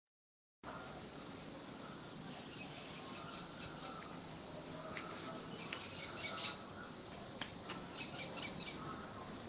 American Kestrel (Falco sparverius)
Detailed location: Valentín Alsina
Condition: Wild
Certainty: Observed, Recorded vocal
Halconcito-colorado.mp3